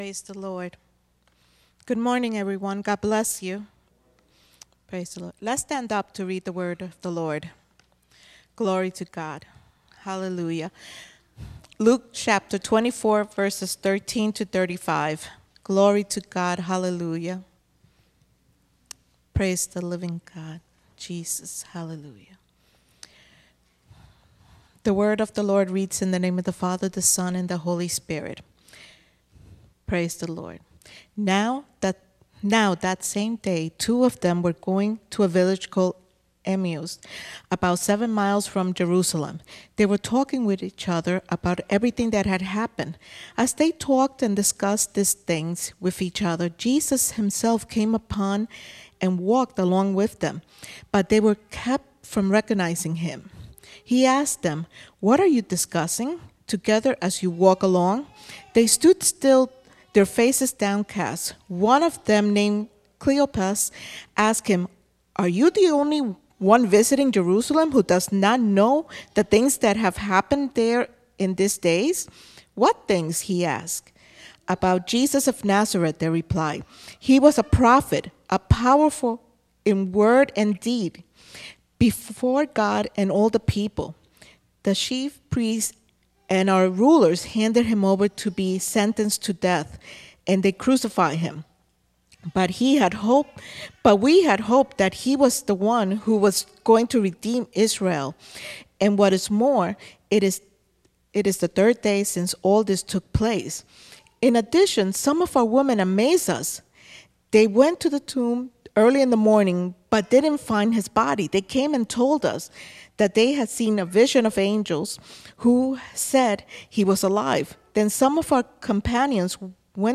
An uplifting message